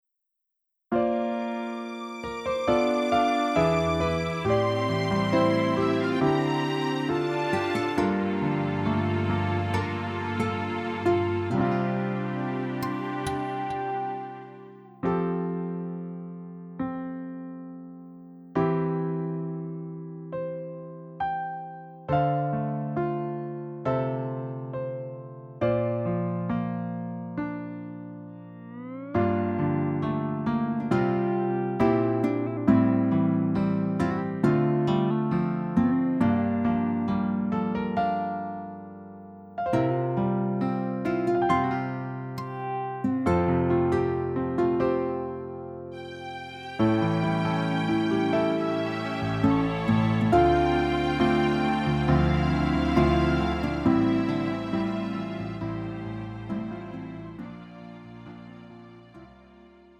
음정 -1키 3:24
장르 가요 구분 Lite MR
Lite MR은 저렴한 가격에 간단한 연습이나 취미용으로 활용할 수 있는 가벼운 반주입니다.